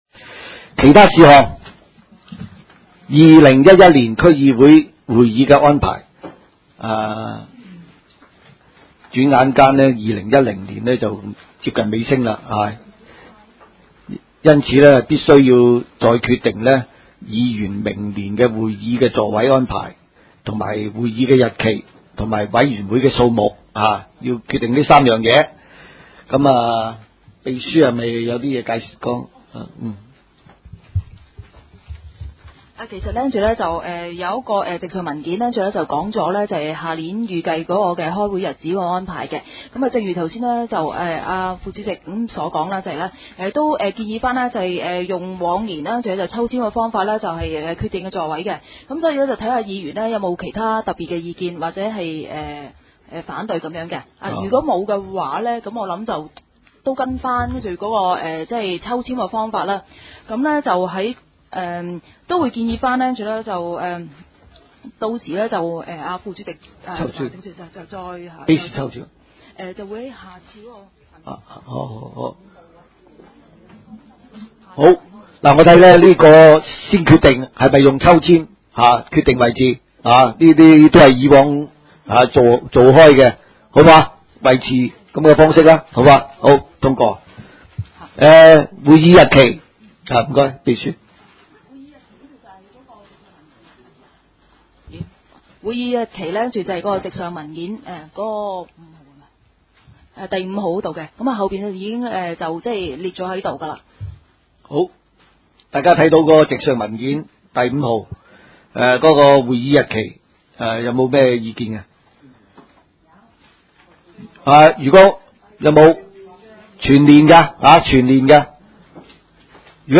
九龍城區議會第十九次會議